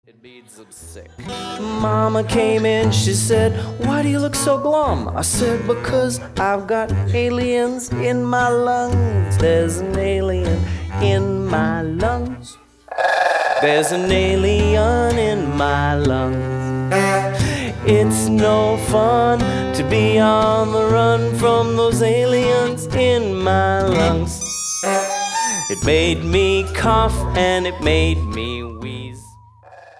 collection of 13 interactive songs.